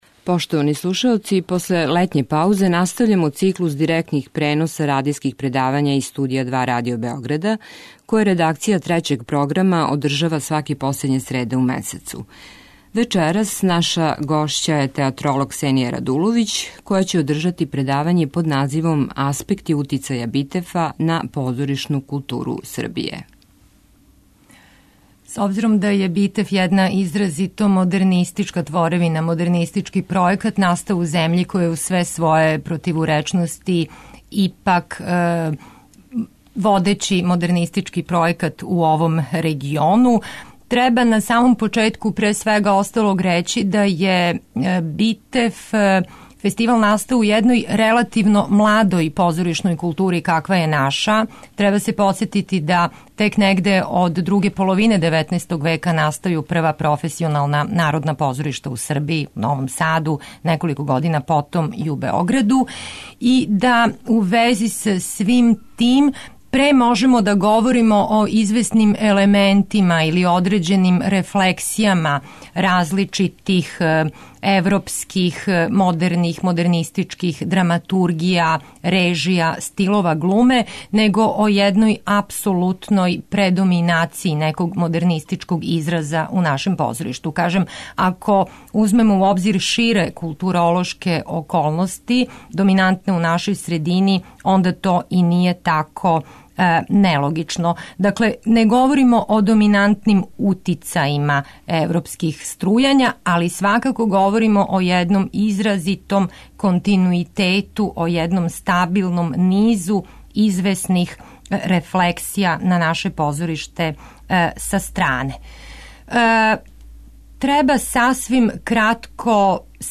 Радијско предавање